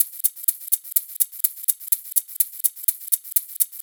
Live Percussion A 15.wav